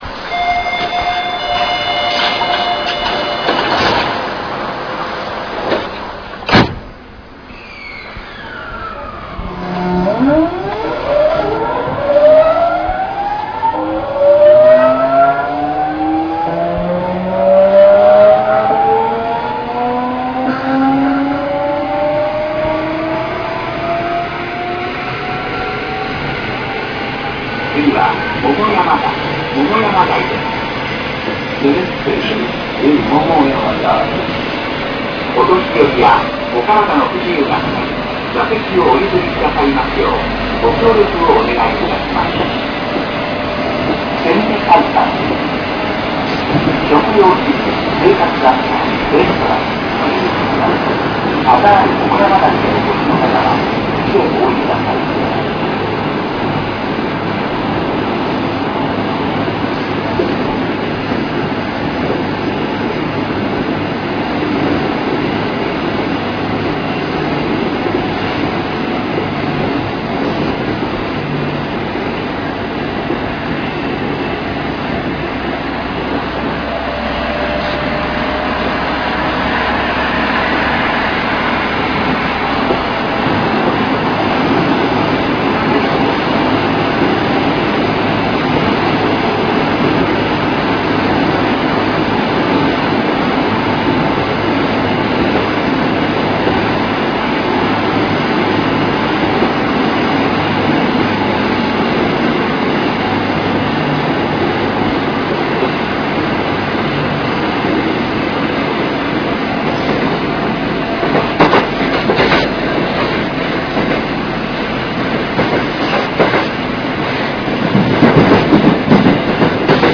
車両の製造はアルナ工機であることや、東芝の制御装置を採用していたり、車内が木目調の 化粧板が使われていたりするところに阪急色が出ています。走行音は大阪市交の20系(4桁車)の 東芝のものに似ています。違うところは減速時の回生失効が早いため、途中で切れてしまうとこ ろでしょう。また北急線内に入ると、さわやかなドアチャイムを鳴らしてくれます。